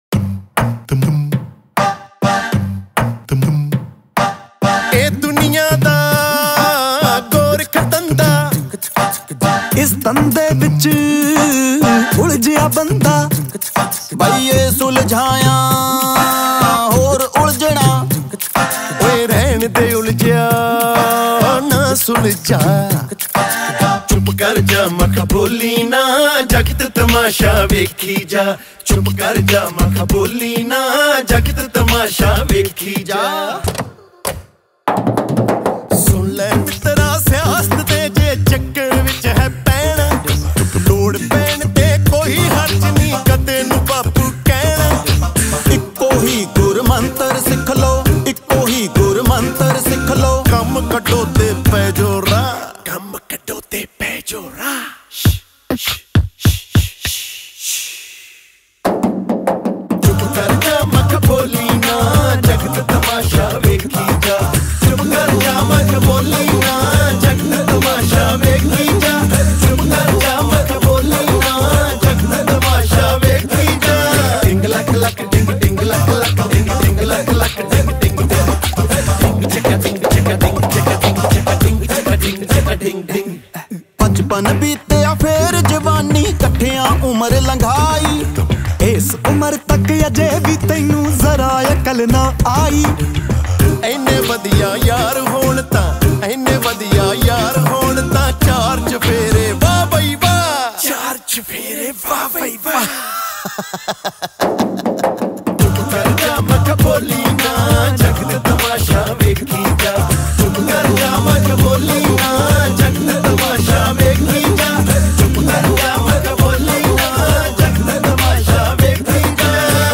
Song Genre : Punjabi Old Song 2